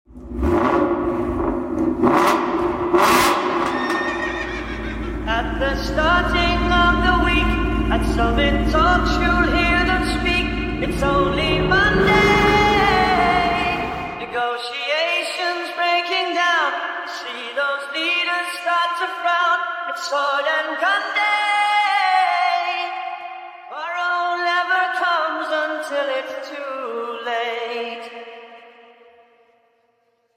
Capristo transforms the F12: aerospace-grade build, sharper throttle, and that raw, high-pitched V12 scream Ferrari should’ve unleashed.